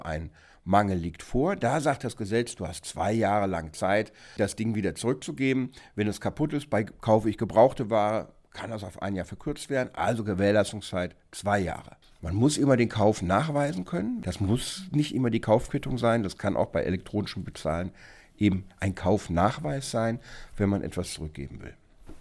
O-Ton2: Umtausch, Reklamation, Widerruf: Wann darf man Waren zurückgeben?